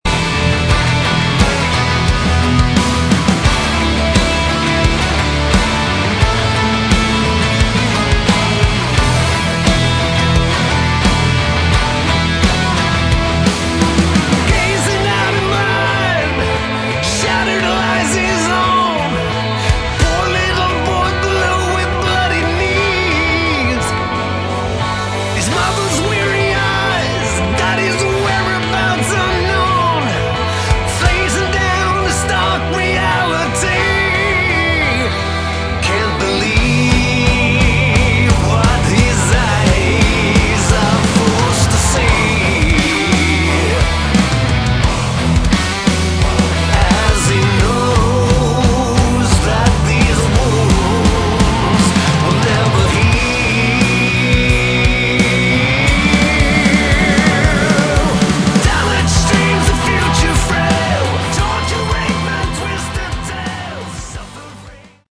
Category: Hard Rock / Melodic Metal
guitars, keyboards
bass
drums